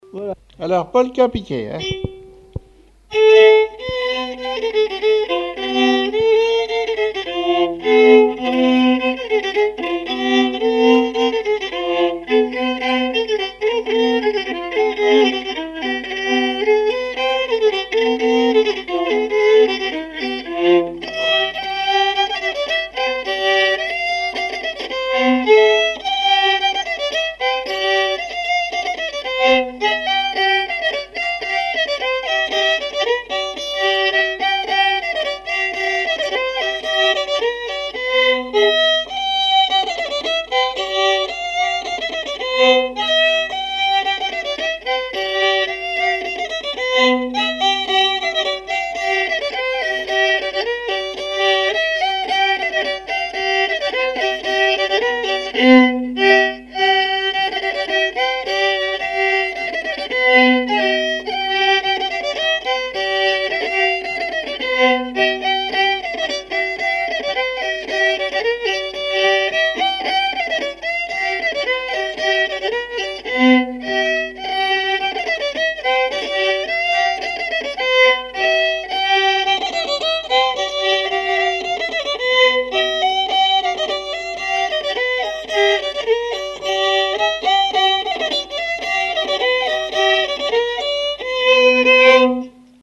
danse : polka piquée
répertoire musical au violon
Pièce musicale inédite